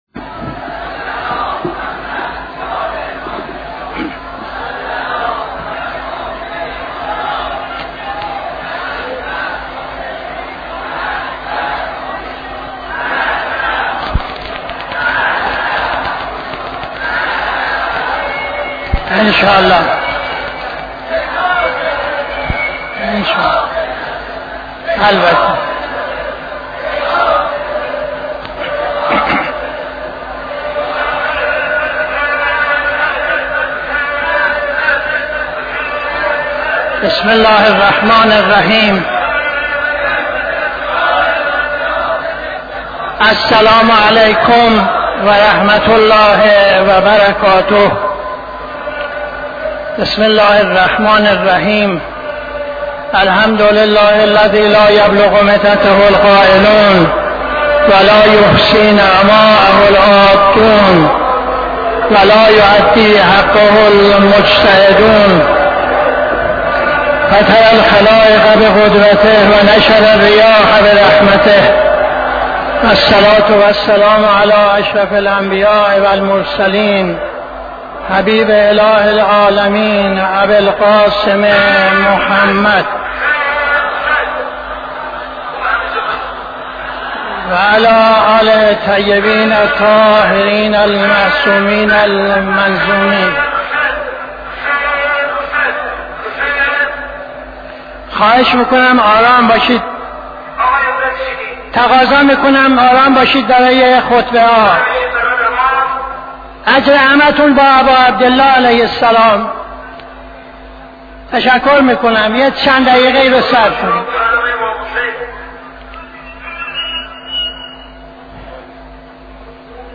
خطبه اول نماز جمعه 23-12-81